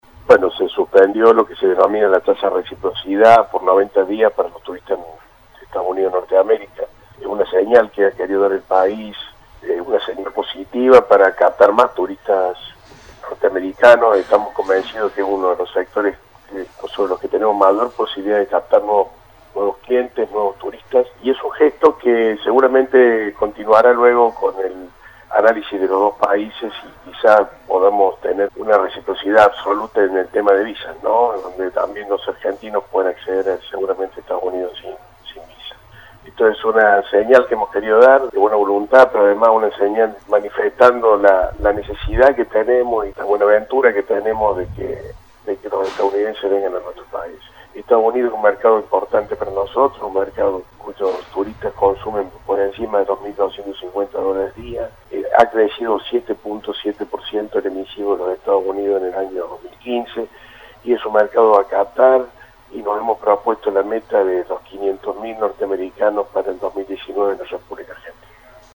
gustavoGUSTAVO SANTOS. ENTREVISTA EXCLUSIVA AL MINISTRO DE TURISMO.
El Ministro de turismo de la República Argentina, Gustavo Santos en una charla telefónica exclusiva con aviacionenargentina, hablo de la actualidad turística y de los temas de coyuntura en la actividad que sin duda marcaran no solo la agenda y los negocios del sector en este año sino en el corto y mediano plazo.